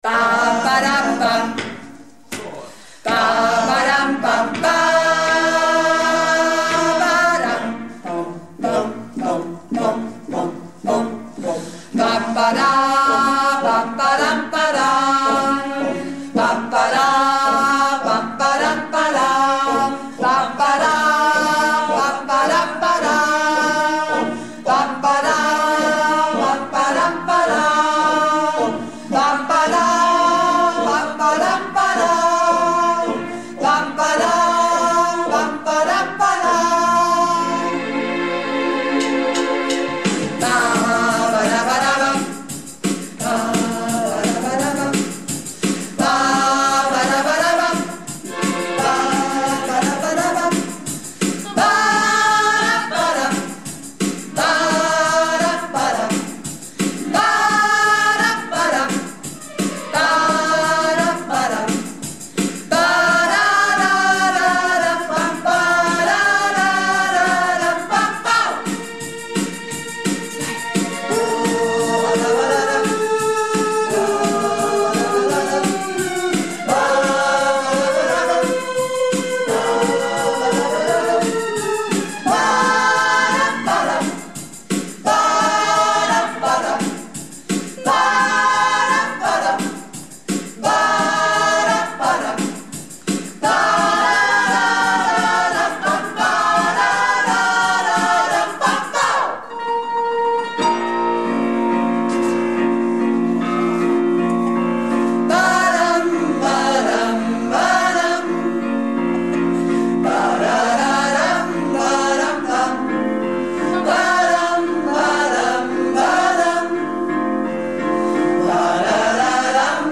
Malle Diven - Probe am 21.03.18